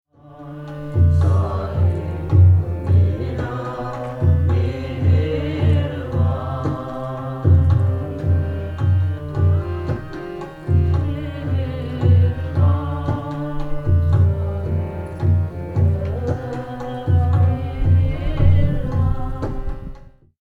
Group worship
worship.mp3